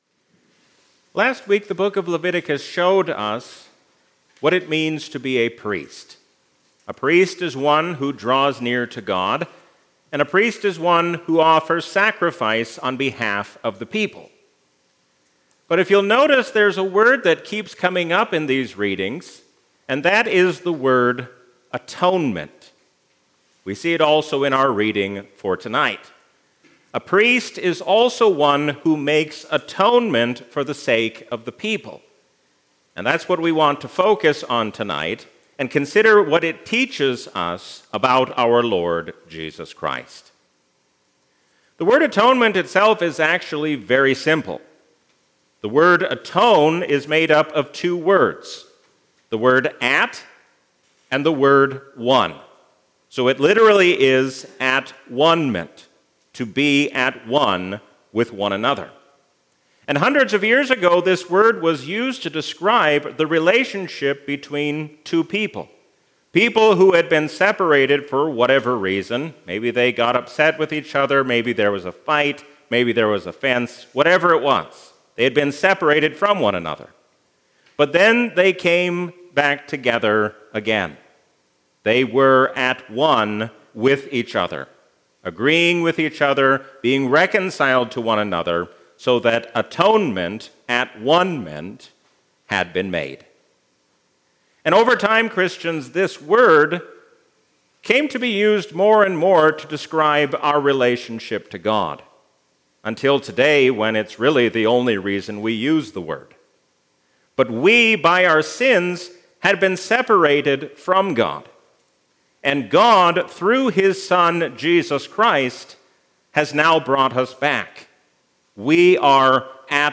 A sermon from the season "Lent 2025."